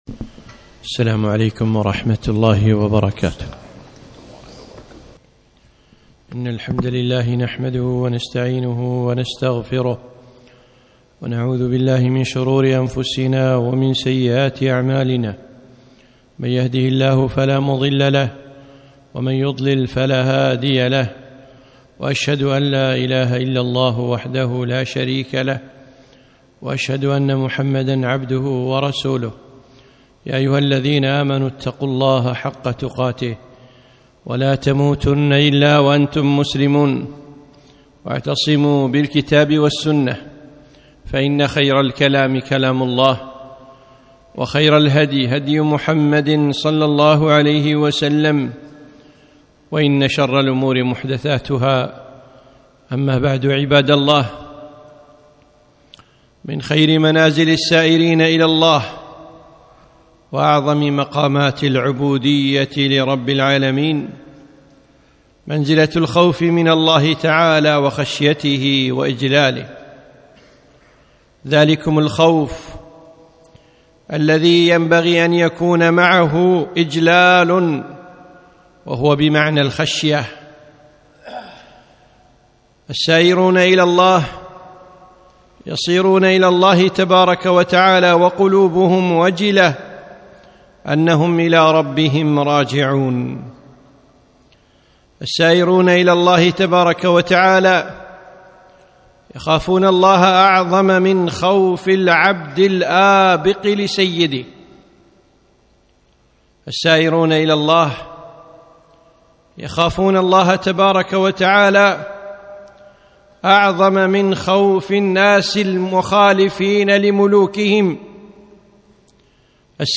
خطبة - الخوف المطلوب